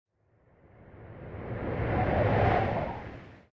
Descarga de Sonidos mp3 Gratis: zas enfoque.
descargar sonido mp3 zas enfoque